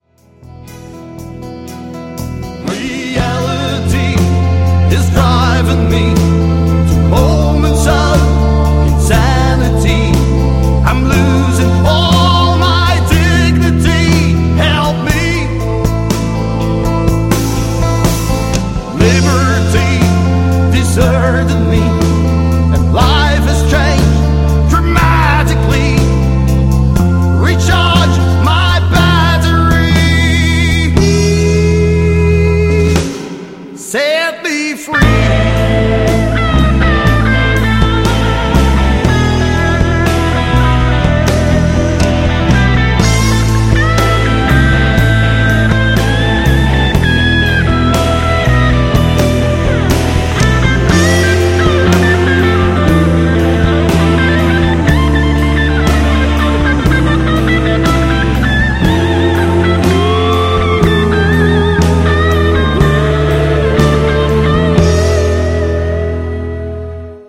Live muziek voor jong en oud